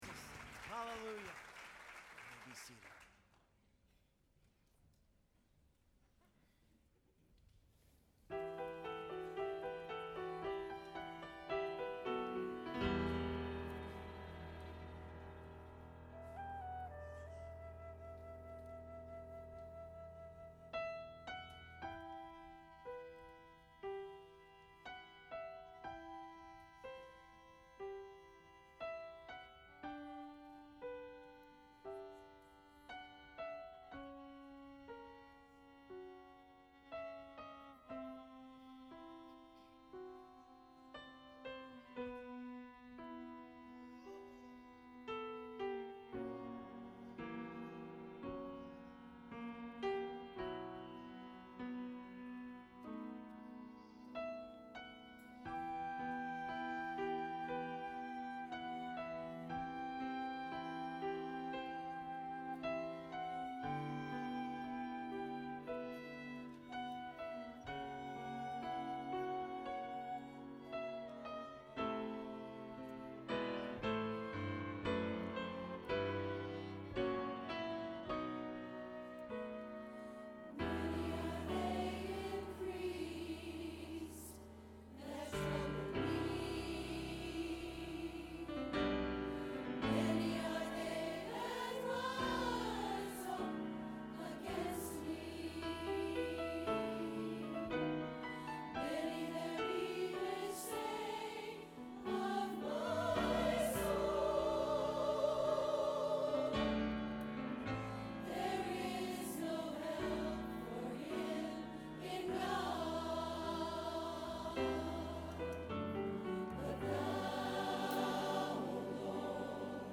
We also shared a song that has become a real favorite of our church called “Thou, O Lord.” It’s an amazing song taken from Psalm 3. Musically it is very dynamic and calls for both nuance and power.
I leave you today with the audio of our choir singing “Thou, O Lord” on Sunday, September 14, 2008.